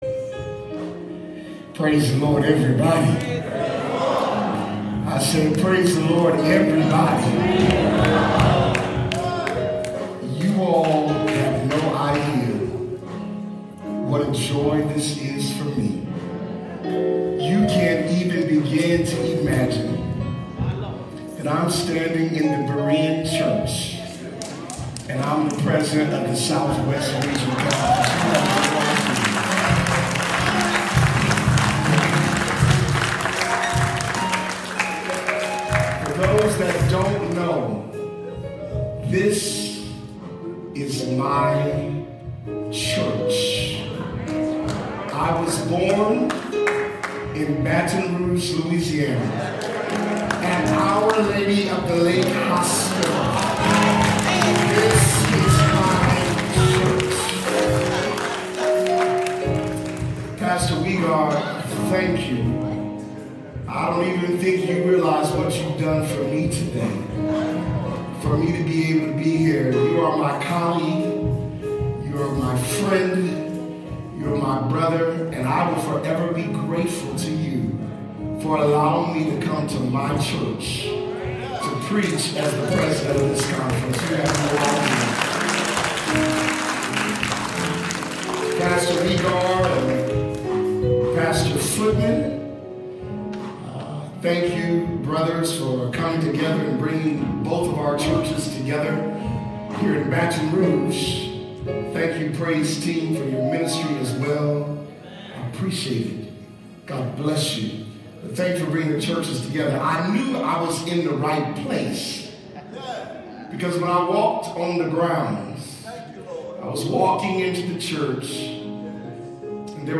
Berean-and-Faith-2021-Ordination-Service.mp3